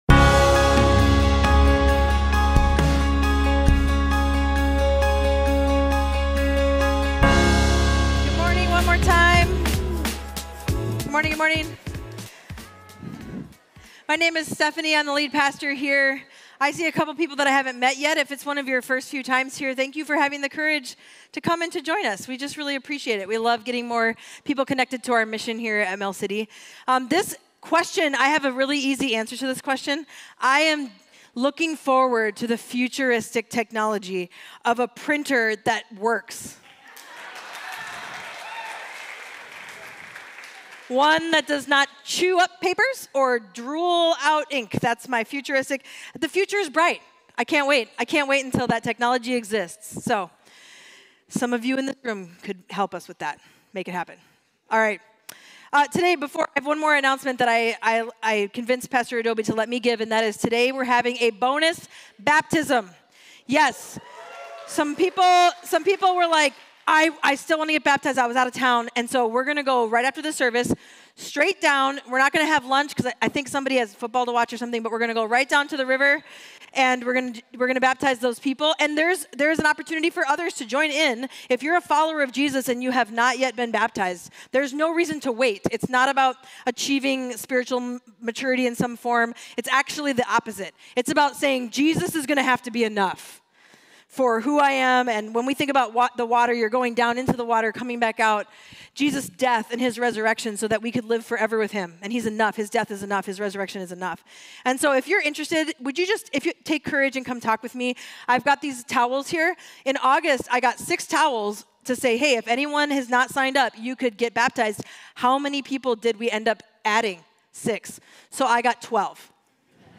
Mill City Church Sermons The Good Life Belongs to the Grieving Sep 30 2024 | 00:36:59 1x 00:00 / 00:36:59 Subscribe Share RSS Feed Share Link Embed